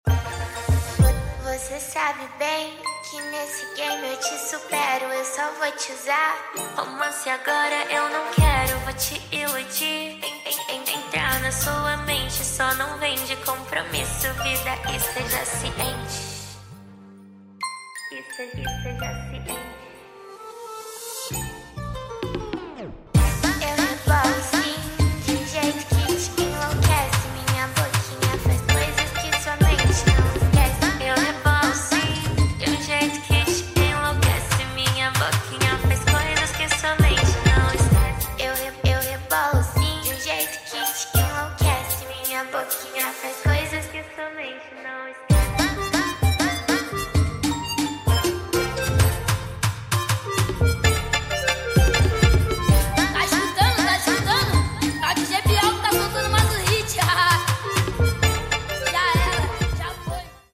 8D 🎧🎶